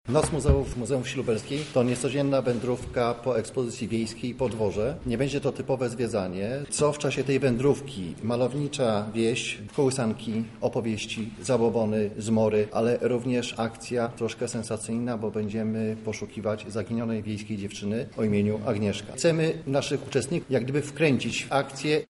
konfa-zamek-noc-muzeów.mp3